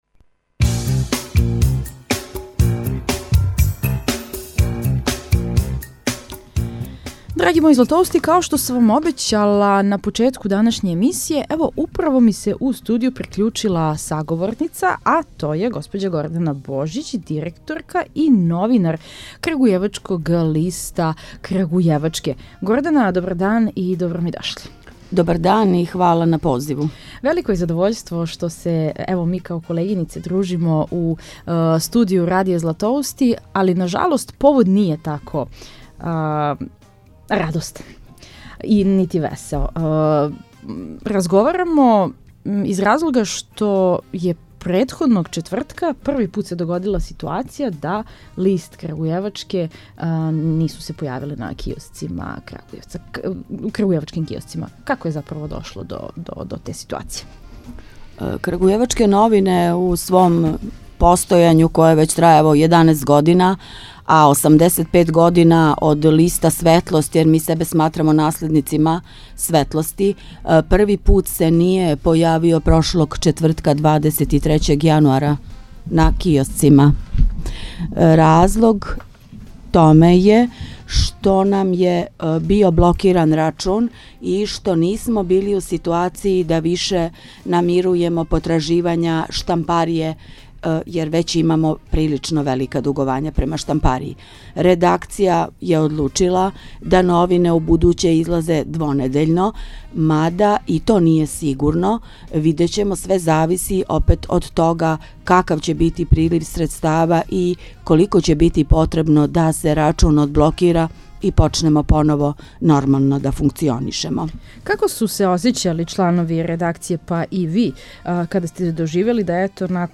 Зашто је Крагујевац у медијском мраку и како смо дошли до тога да 23.јануара не изађе ни тај, један једини крагујевачки лист, сазнајемо у овом разговору.